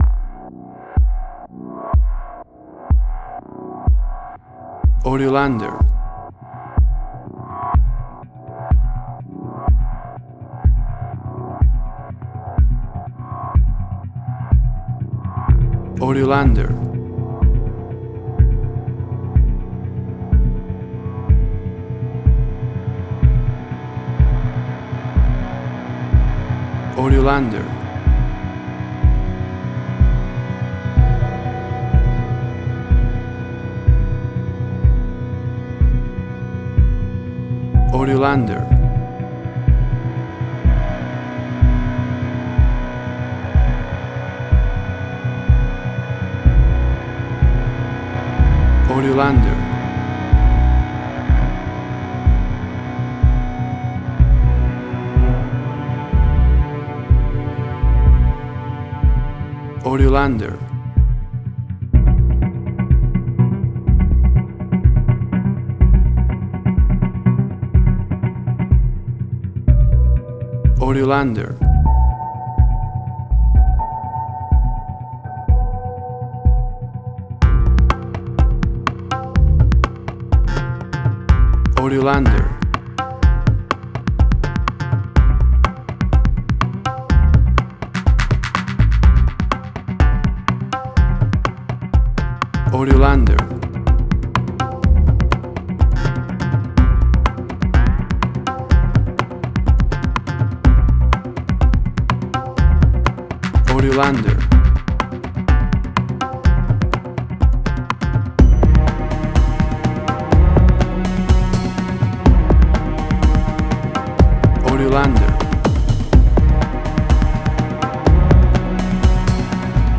Middle Eastern Fusion, action, tension, suspense.
Tempo (BPM): 124